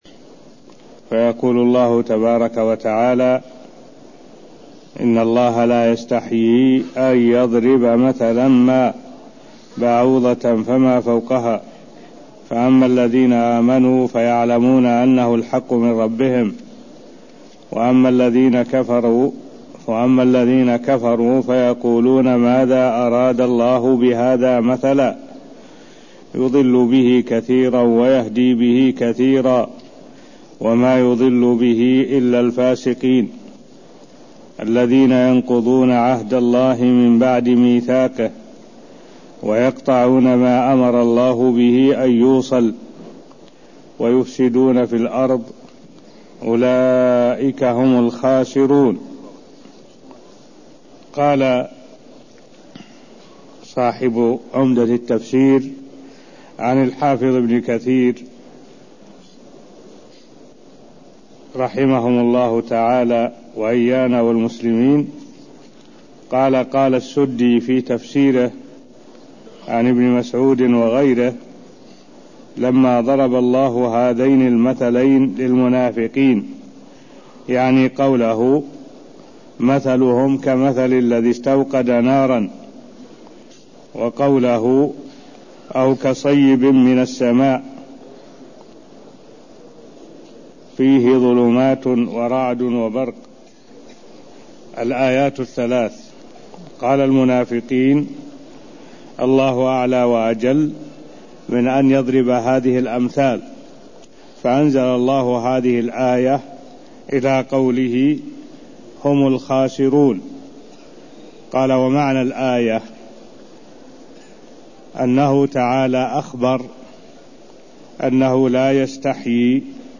المكان: المسجد النبوي الشيخ: معالي الشيخ الدكتور صالح بن عبد الله العبود معالي الشيخ الدكتور صالح بن عبد الله العبود تفسير سورة البقرة آية 26ـ27 (0024)